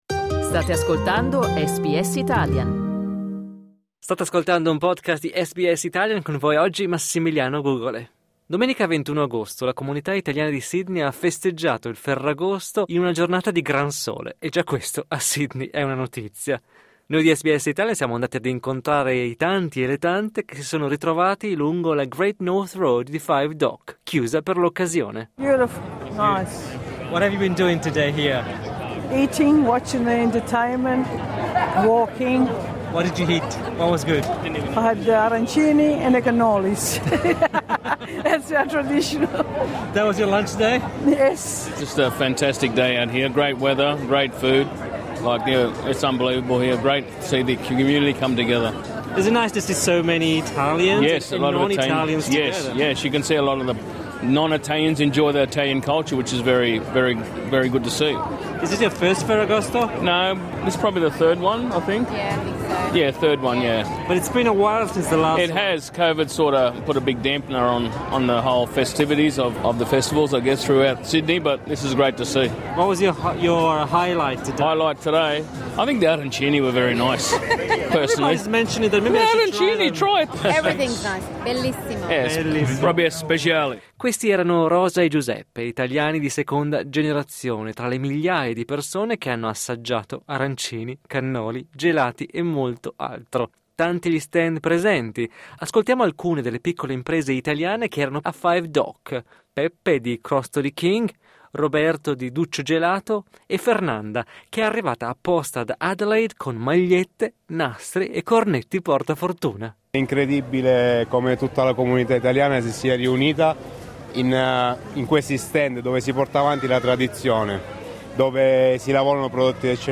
Per la strada si sente molto italiano, a volte mischiato all'inglese e agli immancabili dialetti, ma non sono solo gli italiani ad amare questo evento che vede ogni anno decine di migliaia di persone festeggiare per le strade.
LISTEN TO La migliore pizzeria italiana dell’Asia-Pacifico è a Melbourne SBS Italian 10:24 Italian Rivivi la festa ascoltando il podcast di SBS Italian!